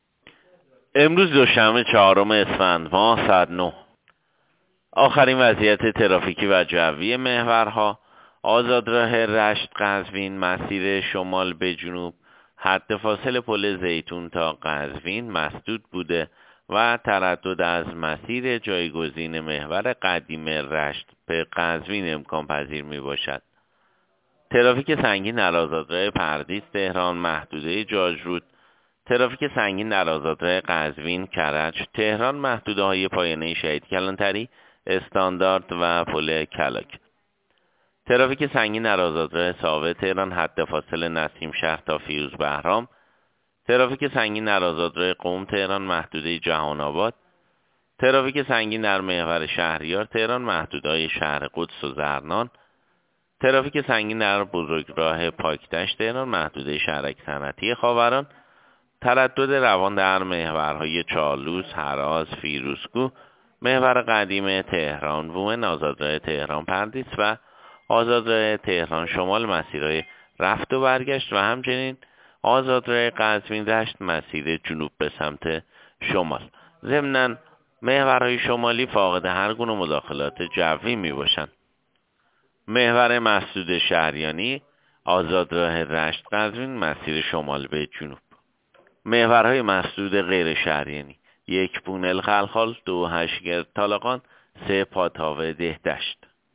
گزارش رادیو اینترنتی از آخرین وضعیت ترافیکی جاده‌ها ساعت ۹ چهارم اسفند؛